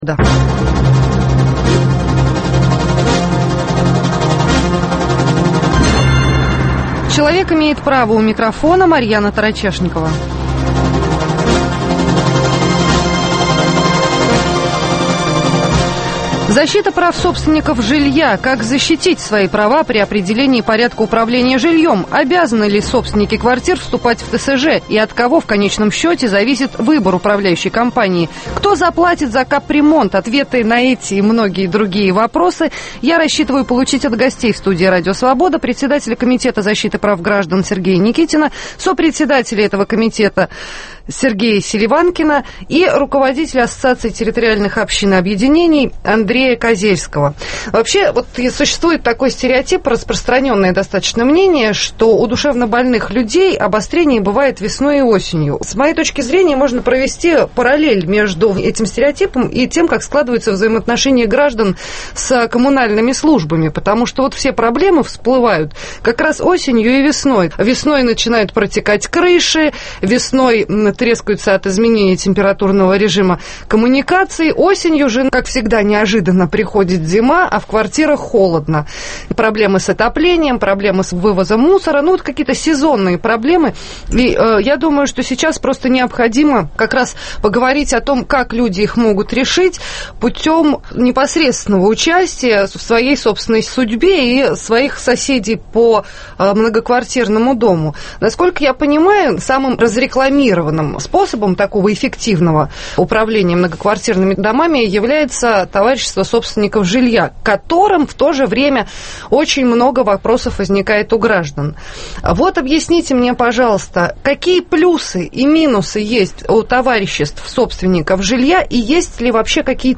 Кто заплатит за капремонт? В студии РС